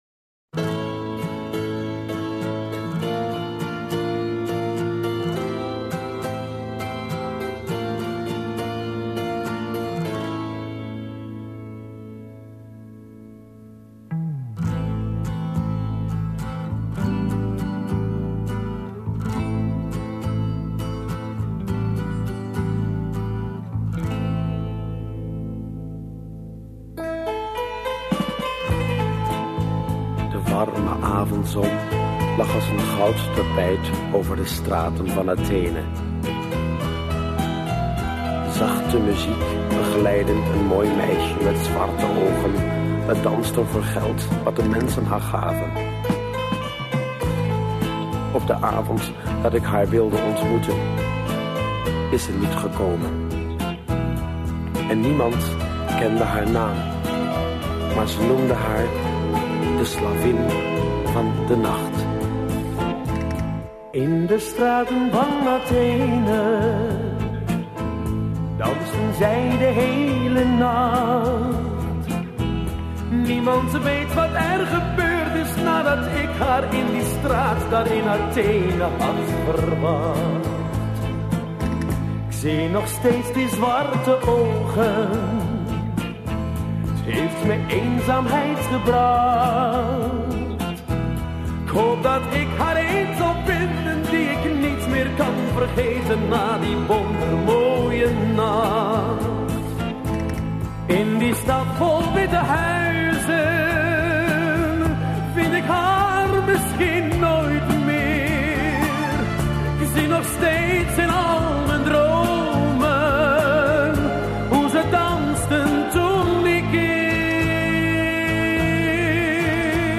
Танцевально.
Да не немецкий это язык, а голландский.